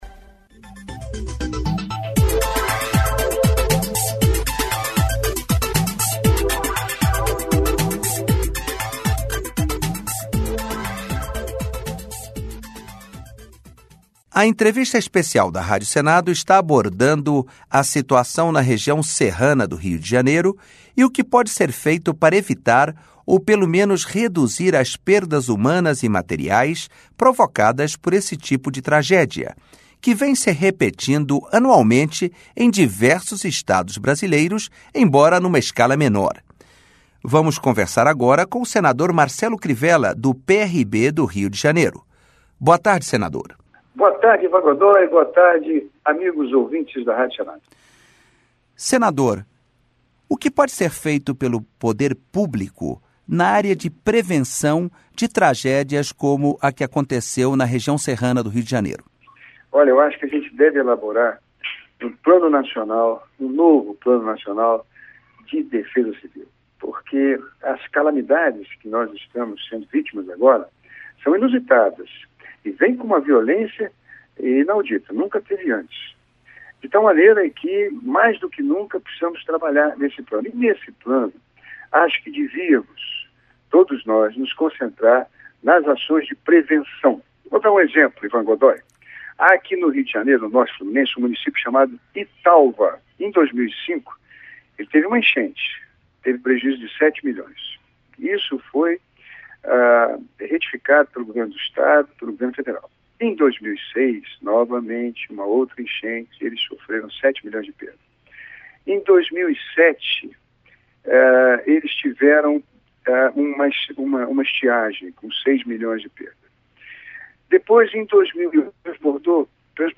Entrevistas com os senadores Francisco Dornelles (PP-RJ) e Marcelo Crivella (PRB-RJ).